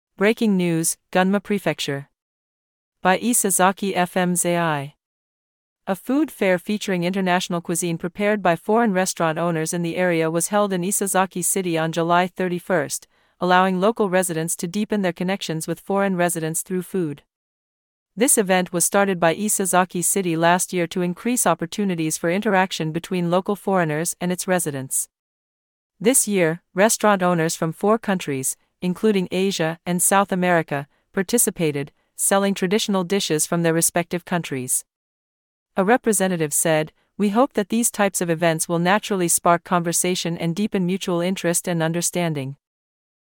Breaking news "Gunma Prefecture".By Isesaki FM's AI.A food fair featuring international cuisine prepared by foreign restaurant owners in the area was held in Isesaki City on July 31st, allowing local…
Audio Channels: 1 (mono)